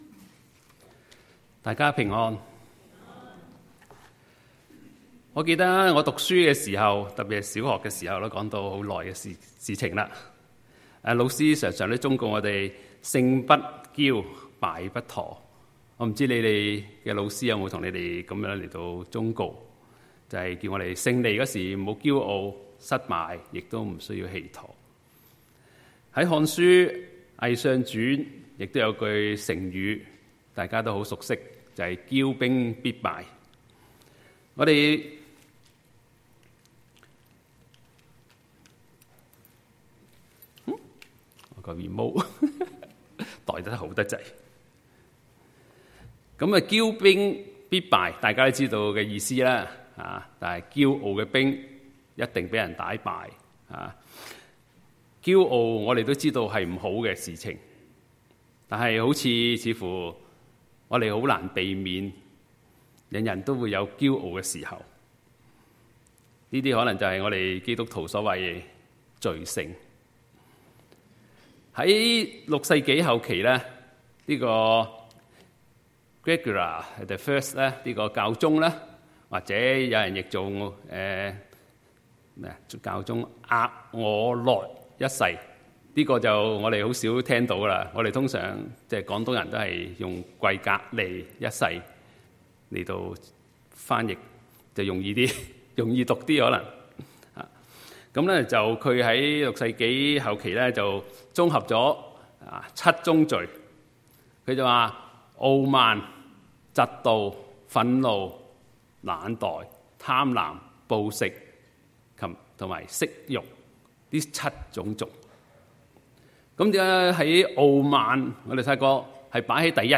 Sermons | Fraser Lands Church 菲沙崙教會